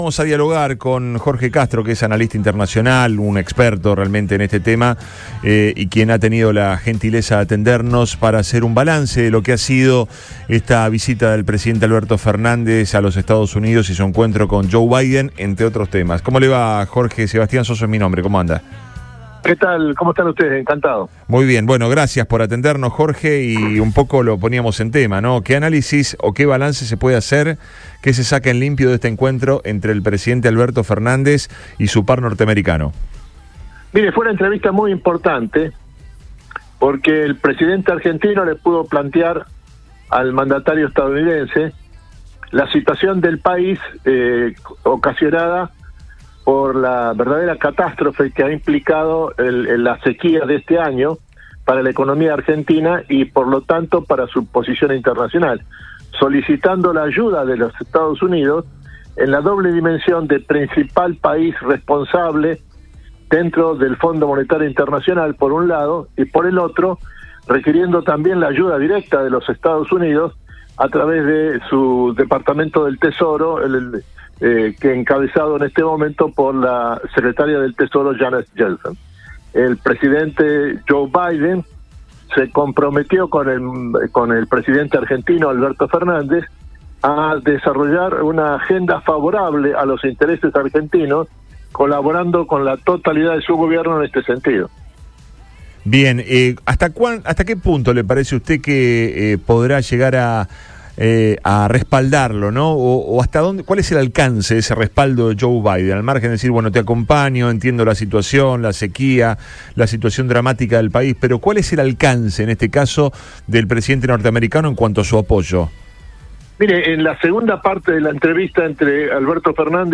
EN RADIO BOING
En este marco, el Analista Internacional Jorge Castro habló en Radio Boing sobre las repercusiones del encuentro entre mandatarios y los planteos realizados desde ambos lados. Además, se refirió a la situación procesal de Trump.